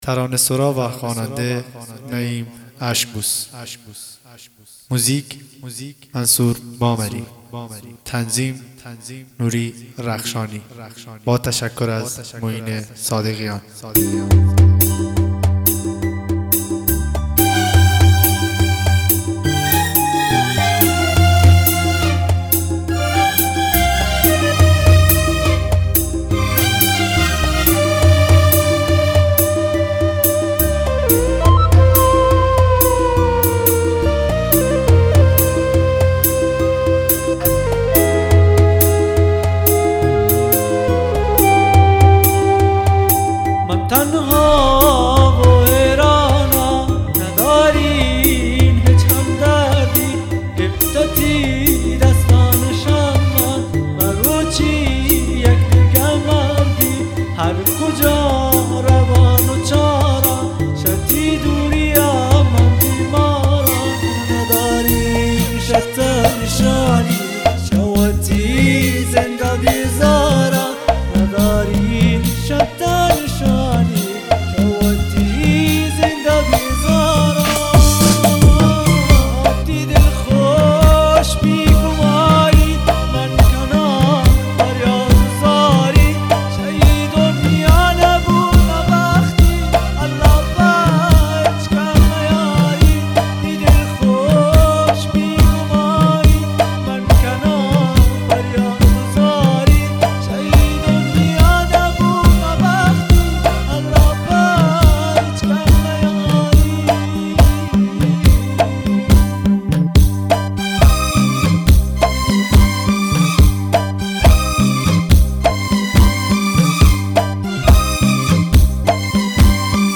اهنگ بلوچی